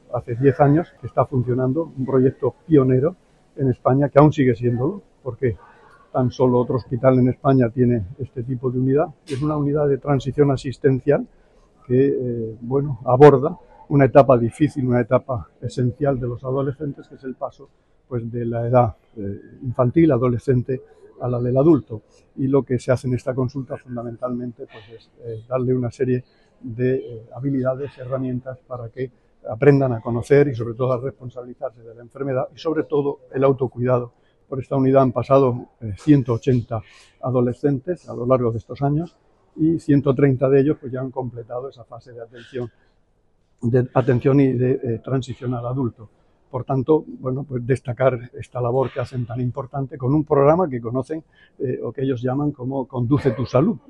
Declaraciones del consejero de Salud sobre el programa de transición a la vida adulta de los pacientes con patología renal.